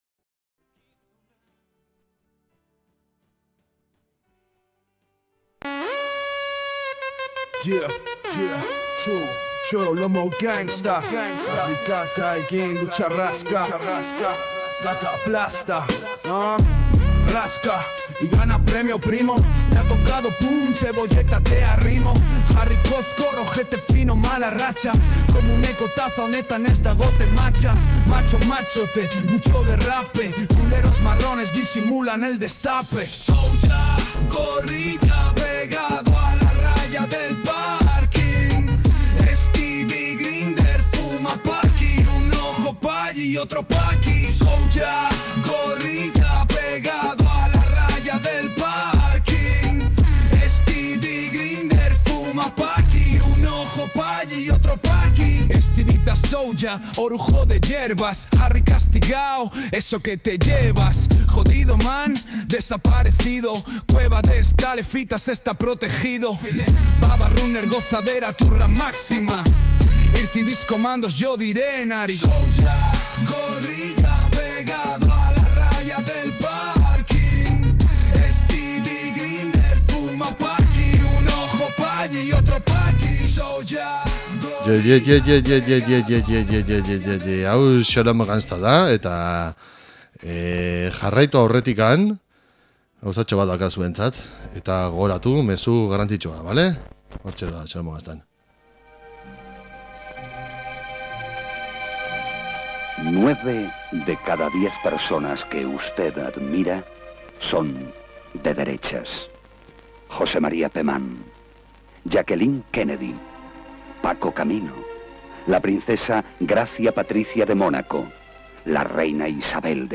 Mundu osoko Rapa, entrebistak zuzenean, Bass doinuak eta txorrada izugarriak izango dituzue entzungai saio honetan.